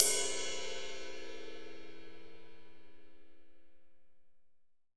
Index of /90_sSampleCDs/AKAI S6000 CD-ROM - Volume 3/Ride_Cymbal2/JAZZ_RIDE_CYMBAL